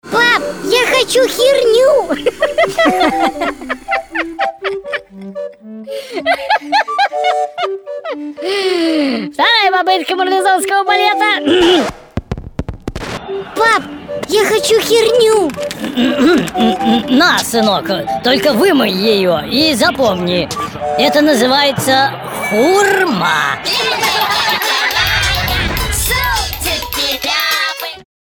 Короткие аудио анекдоты от “ШОУ ШЕПЕЛЯВЫХ”